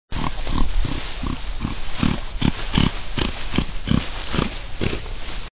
جلوه های صوتی
دانلود صدای خر خر کردن خر از ساعد نیوز با لینک مستقیم و کیفیت بالا